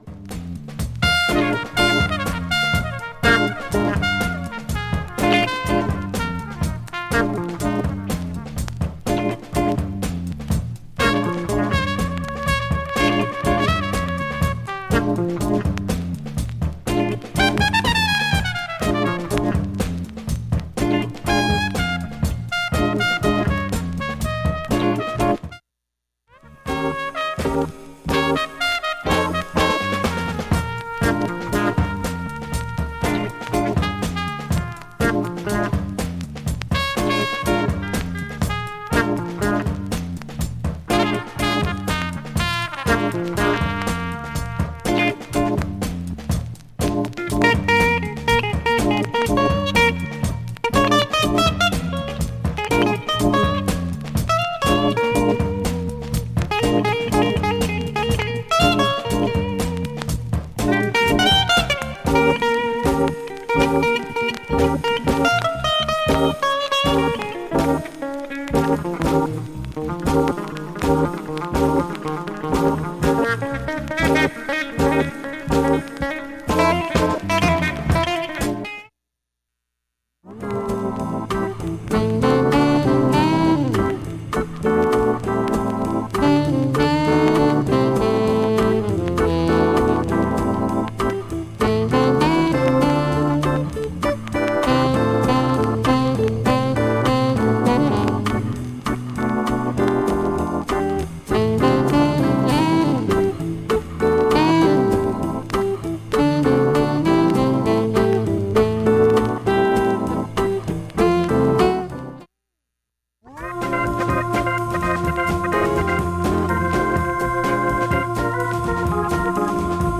バックチリはかなり小さめなので、
プツ自体はストレスはありません。
針飛びはありませんでした。
1,A-1中盤にプツが8回と22回出ます。
2分ほどで中盤からは単発プツになります。
単発のかすかなプツが１８箇所